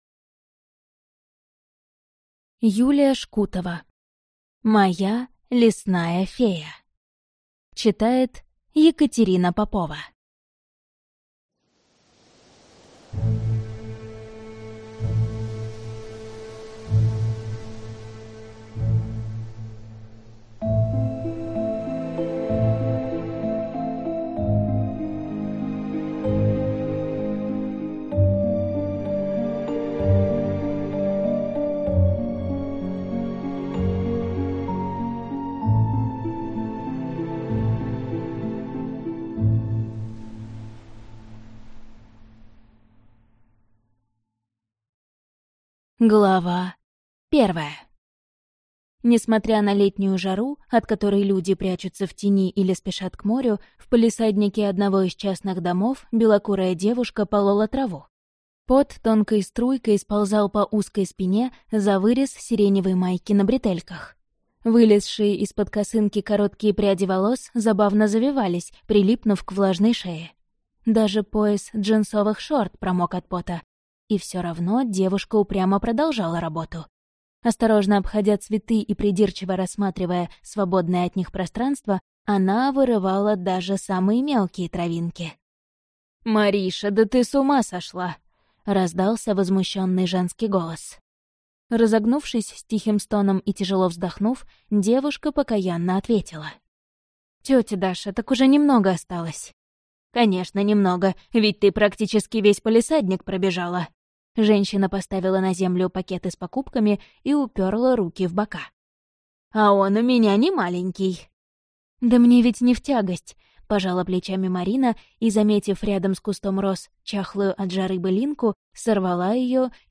Аудиокнига Моя лесная фея | Библиотека аудиокниг